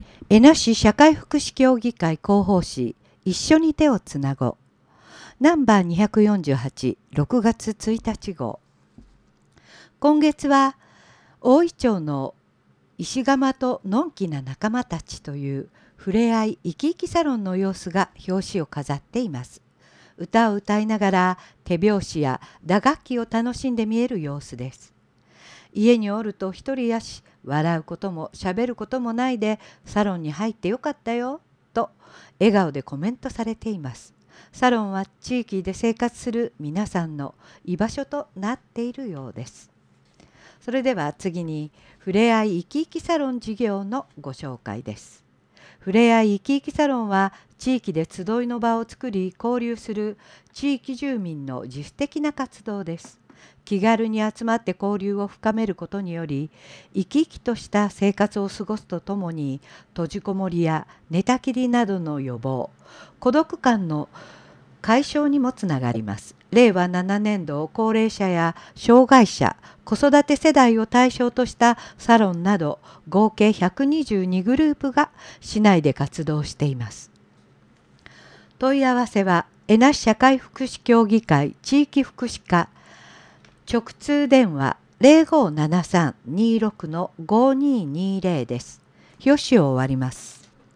広報紙音声版はこちら↓